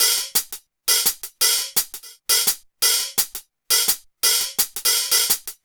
Hi Hat 07.wav